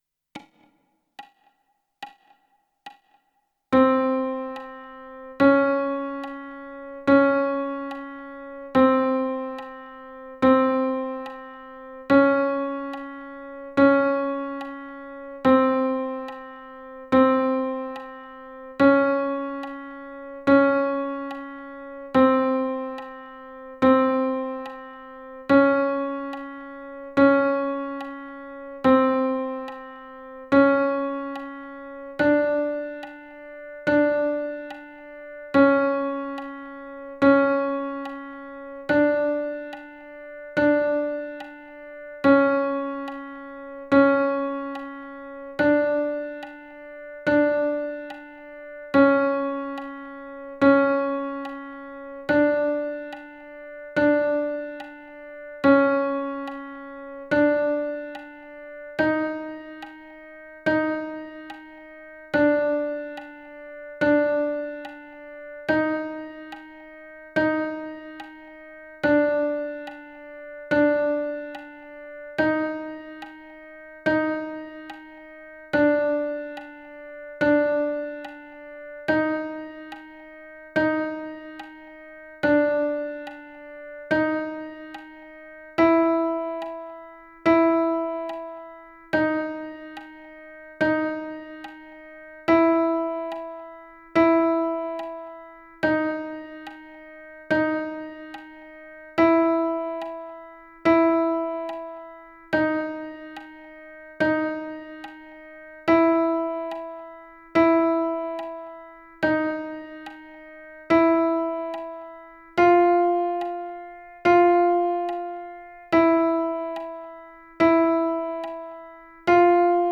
Here some playtalong tracks to train the chromatic notes on the bansuri.
There are more musical approaches like approach notes and enclosures, applied to scales and arpeggios, but this one here is simply moving up the whole range of the flute, one by one.
Playalong-Chromatic-Trainer-F-Flute-small-range.mp3